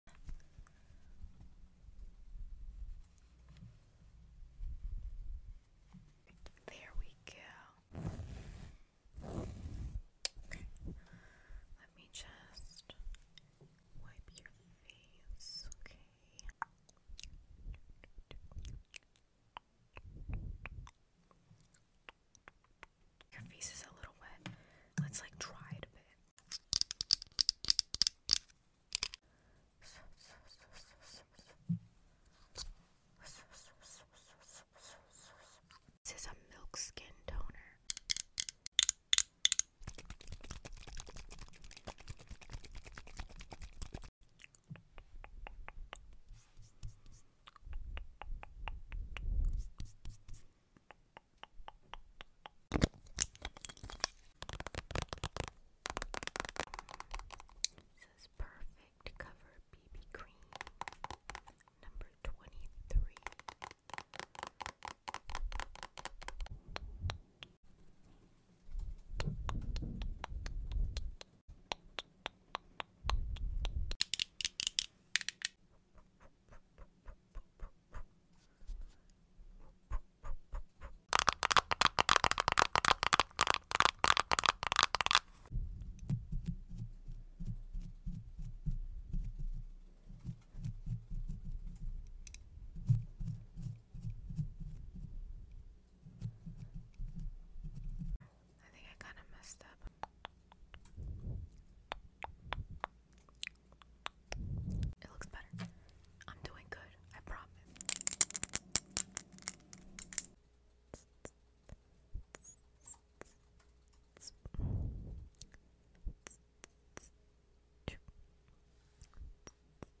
ASMR POV You Let Me Sound Effects Free Download